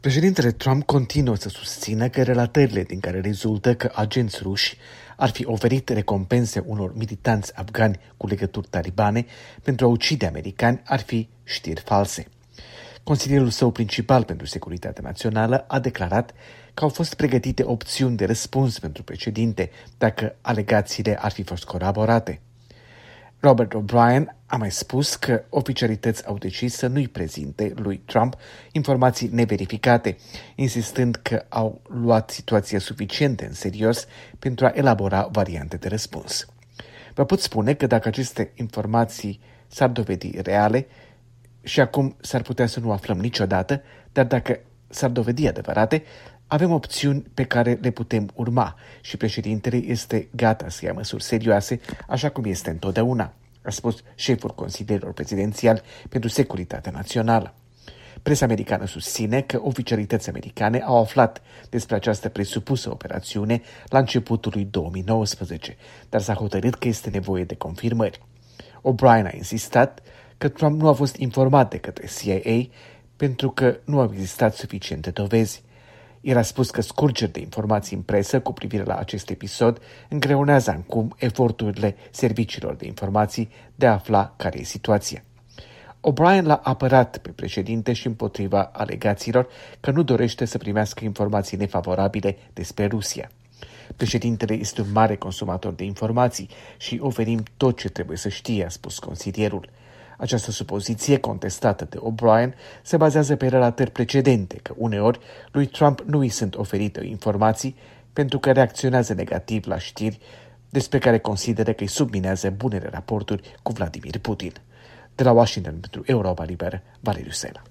Corespondență de la Washington: Trump & Putin& G7